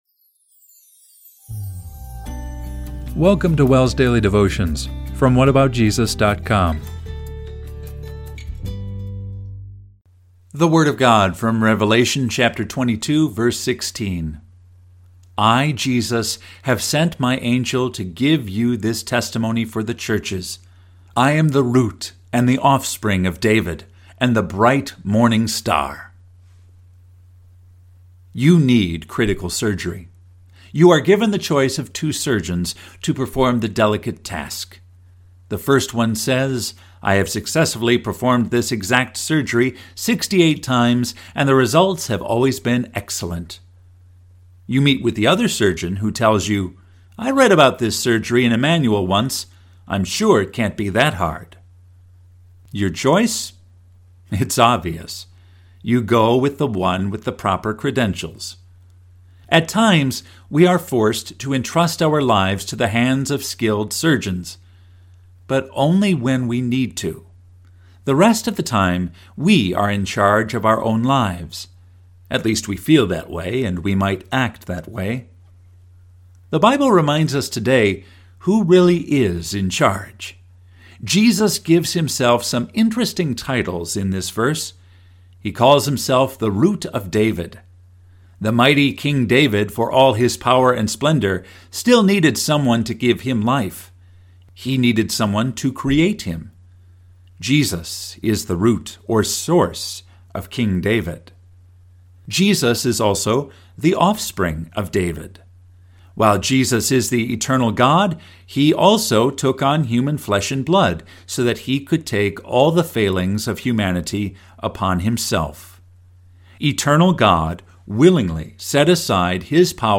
Daily Devotion – June 5, 2025